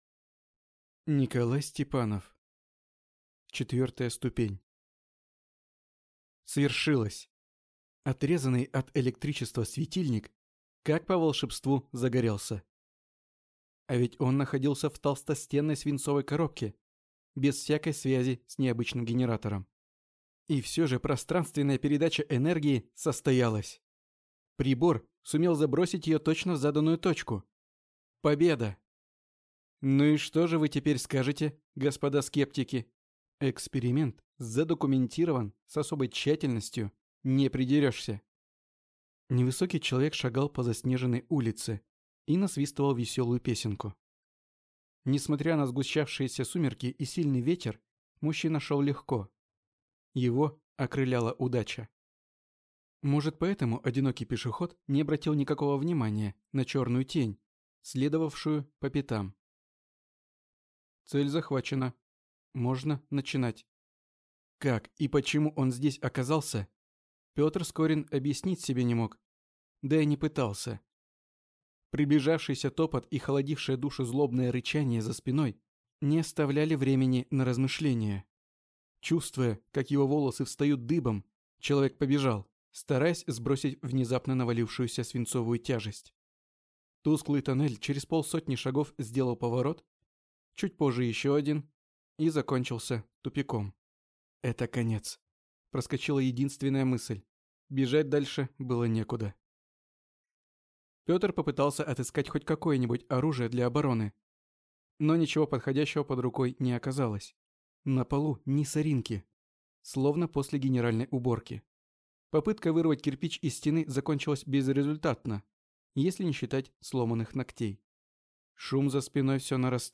Аудиокнига Четвертая ступень | Библиотека аудиокниг